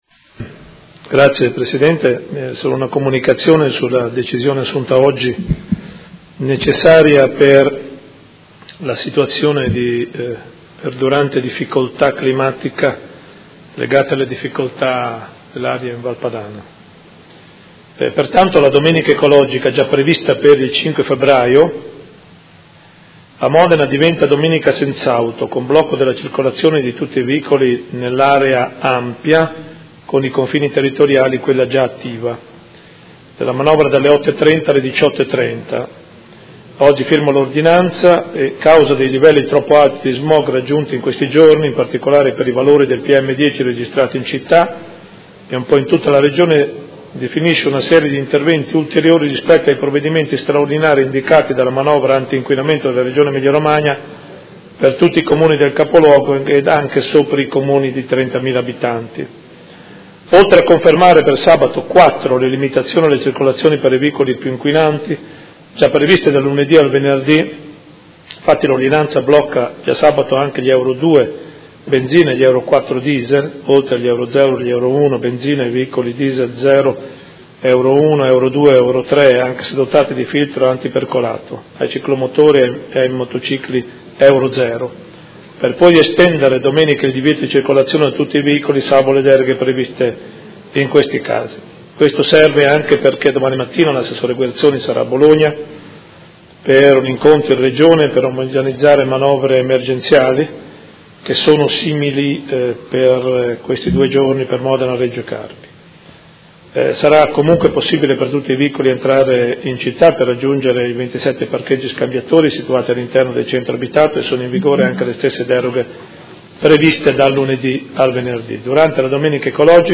Comunicazione del Sindaco in tema di provvedimenti urgenti in risposta all'inquinamento dell'aria